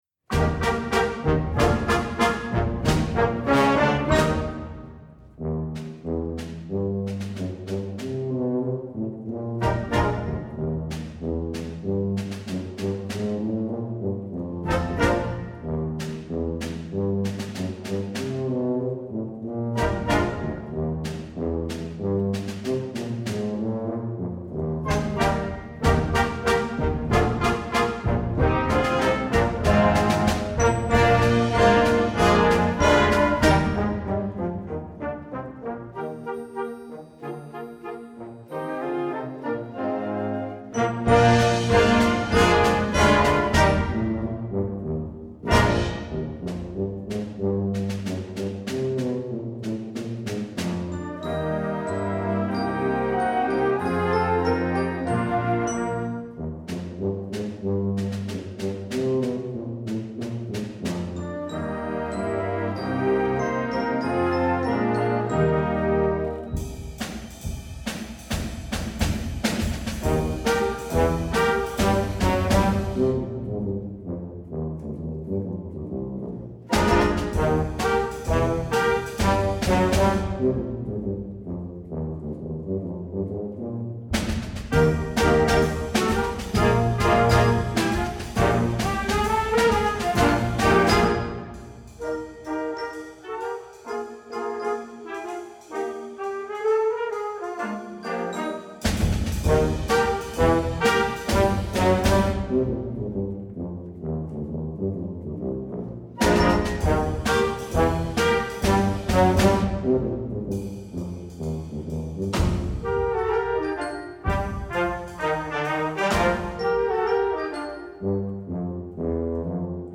Voicing: Tuba Section w/ Band